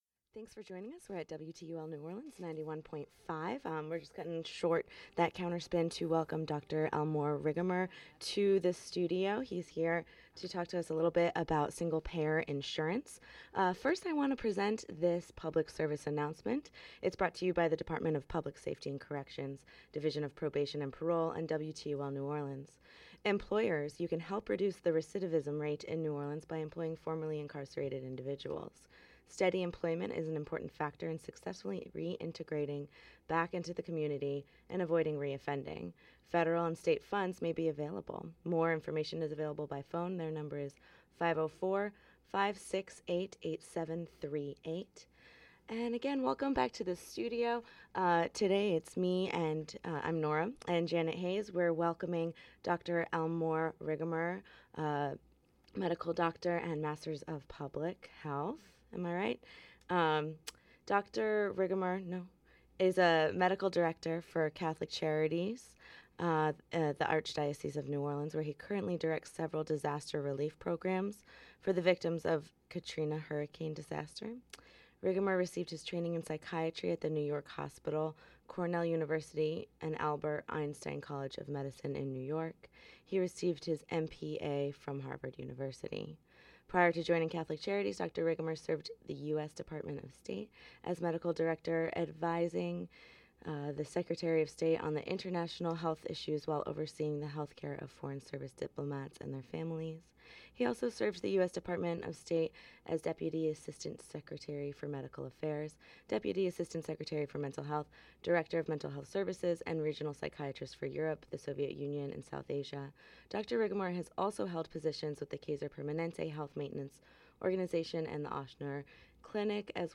Interview on Single Payer Health Care